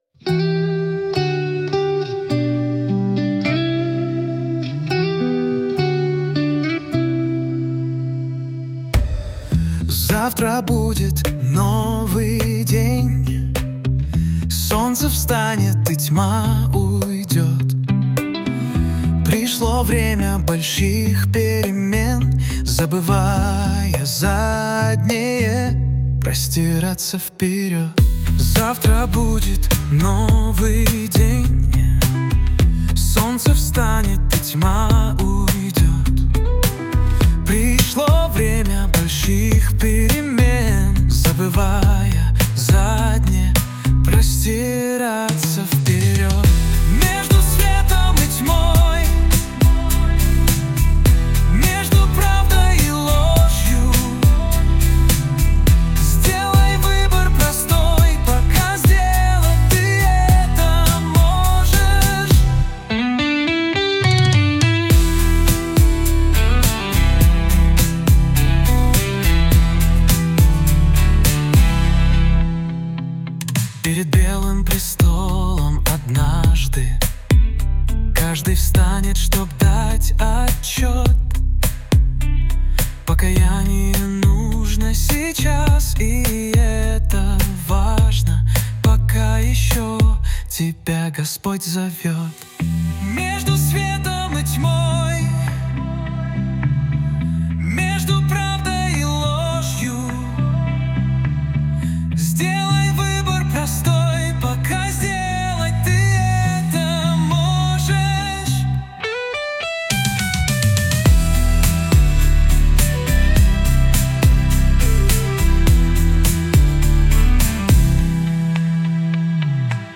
песня ai
245 просмотров 1125 прослушиваний 62 скачивания BPM: 104